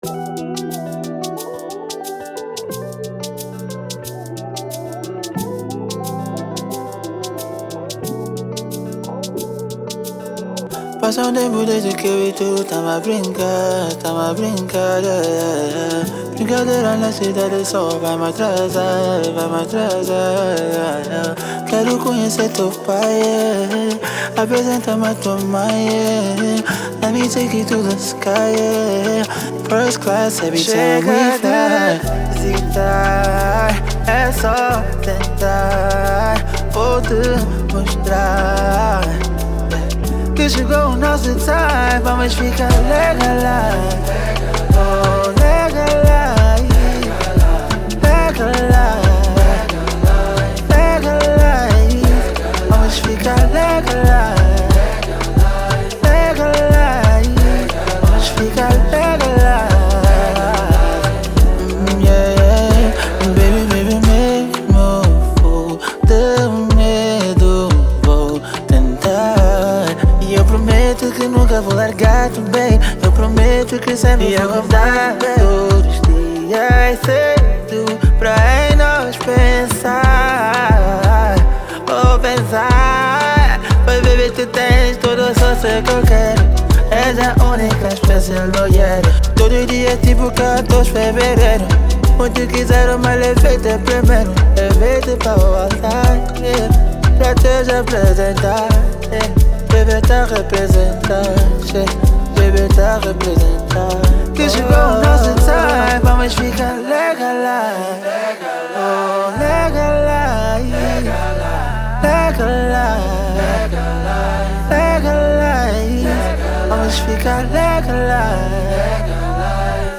Genero: Pop